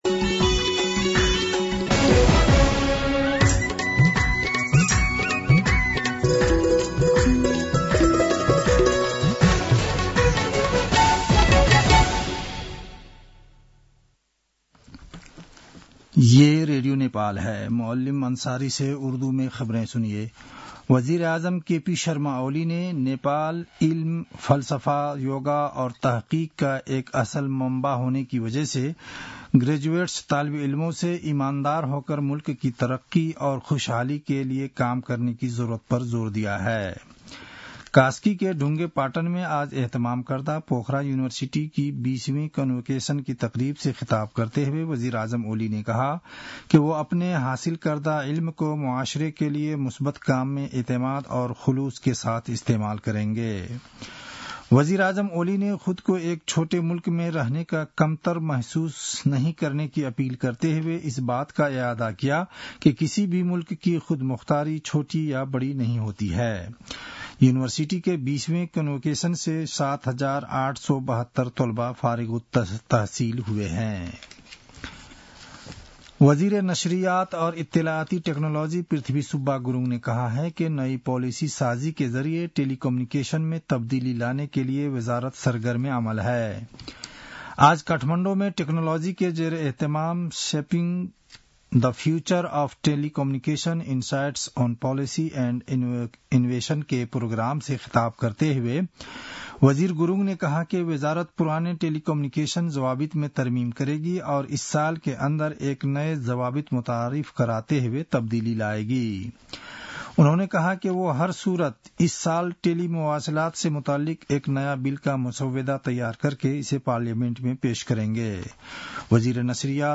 उर्दु भाषामा समाचार : ९ फागुन , २०८१